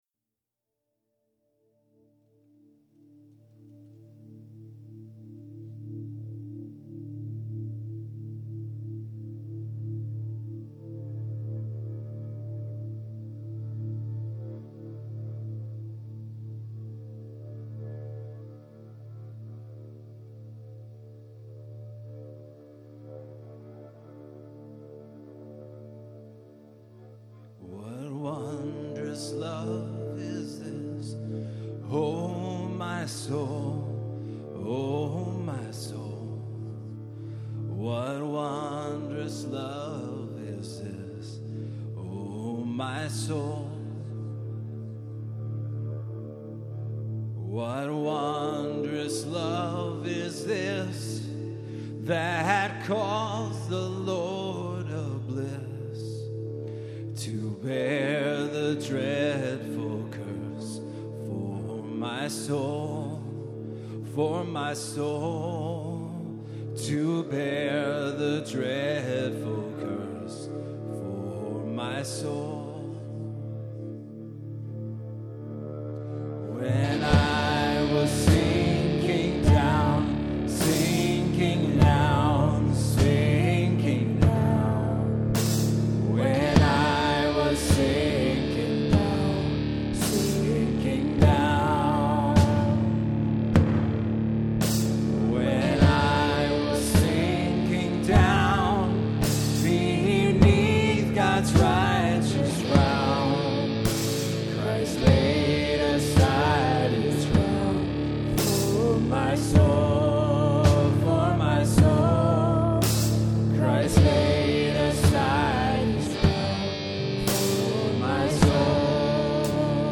Performed live at Terra Nova - Troy on 11/1/09.